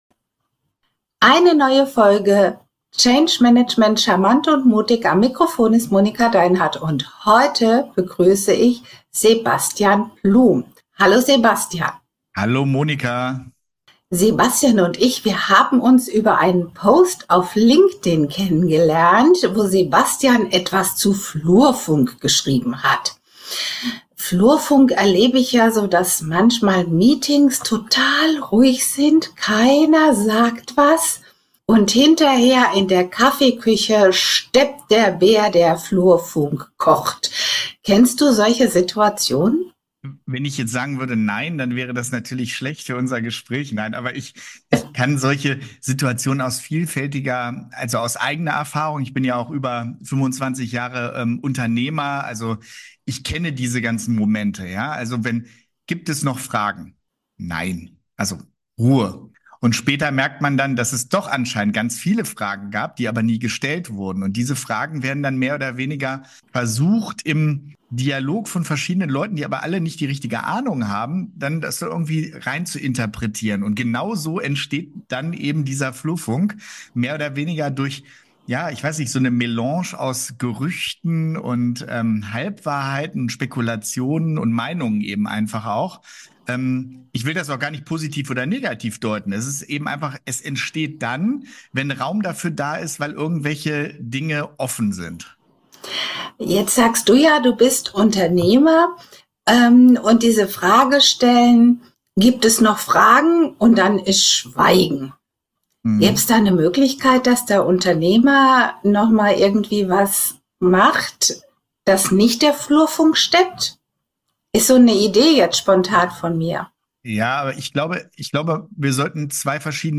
Heute sprechen wir mit unserem Gast